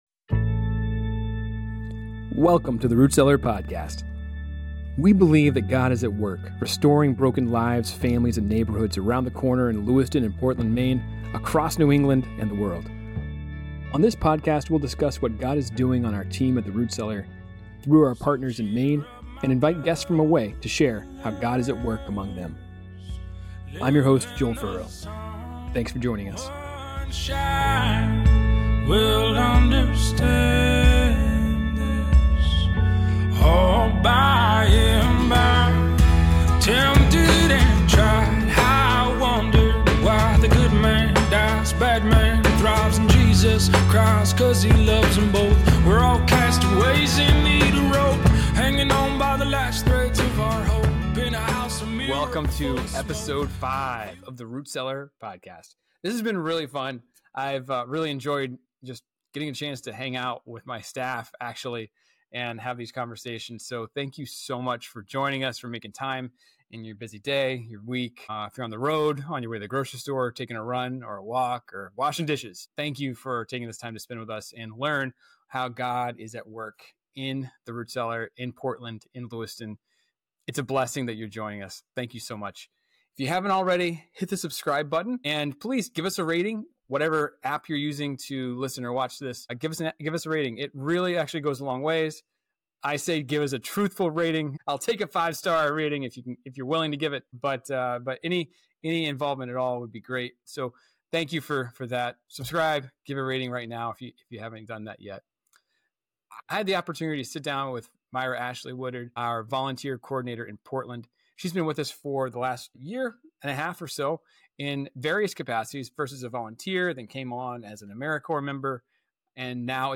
This conversation explores the experiences of immigrants learning English and integrating into American society.